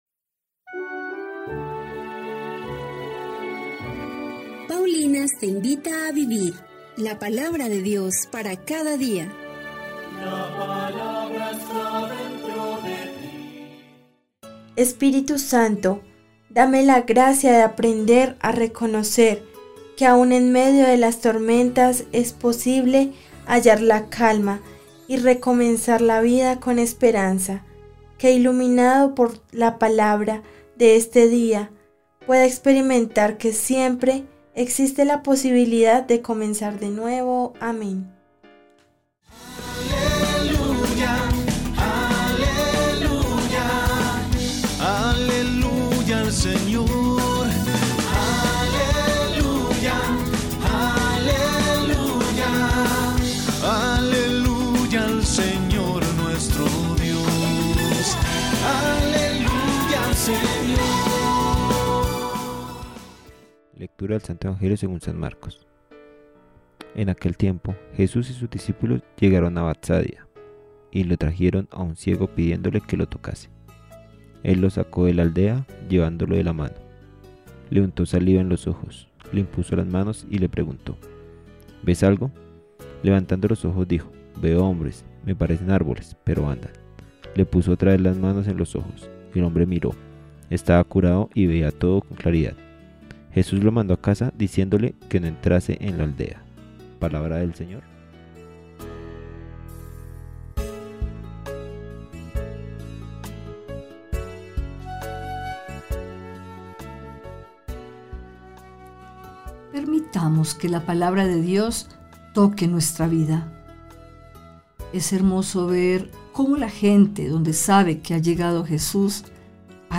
Lectura del libro del Levítico 19, 1-2. 11-18